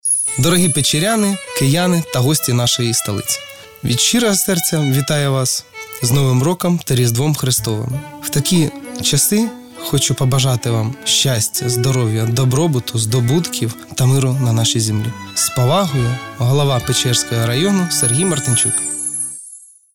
Голова Печерської райдержадміністрації Сергій Мартинчук записав новорічне привітання киян, яке буде транслюватиметься на радіо «Київ 98FM» під час свят.